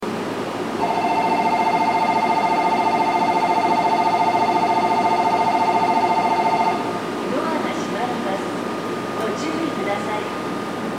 長浦駅　Nagaura Station ◆スピーカー：小VOSS
発車メロディーは導入されておらず、発車の際、発車ベルが流れます。
2番線発車ベル